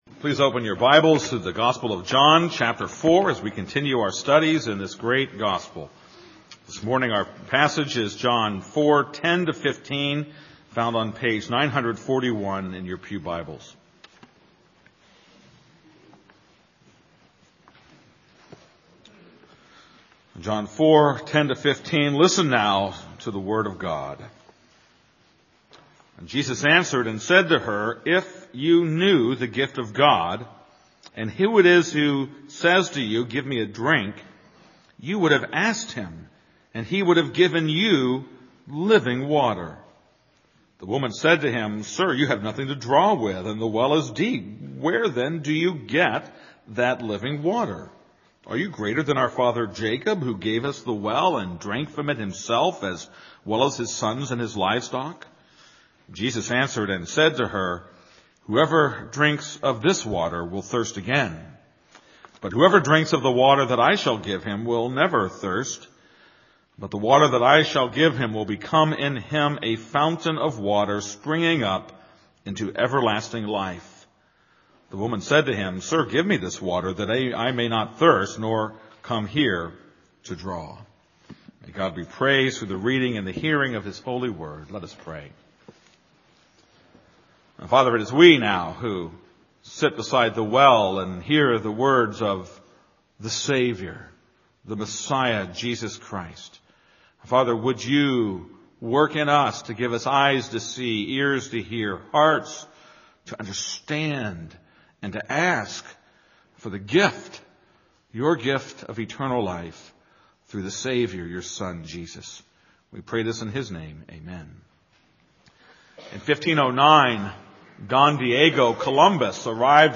This is a sermon on John 4:5-10.